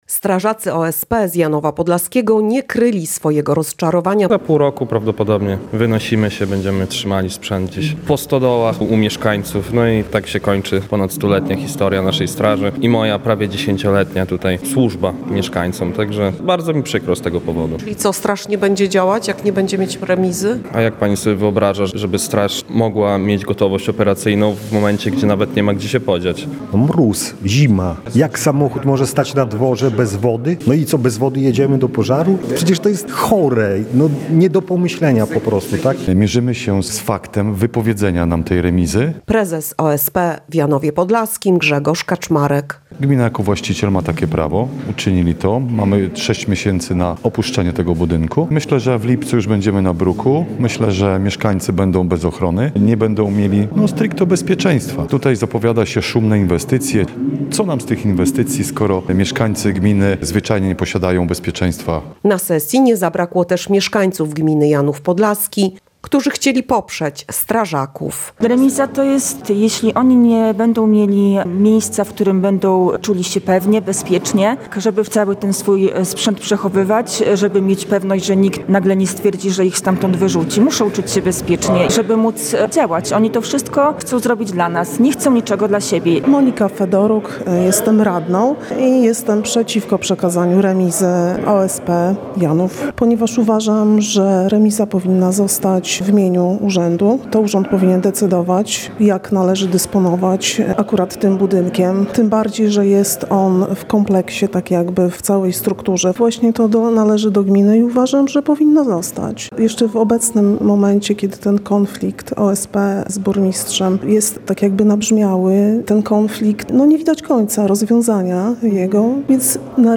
Dziś radni miasta nie wyrazili jednak zgody na takie rozwiązanie. Na sesji obecna była nasza reporterka.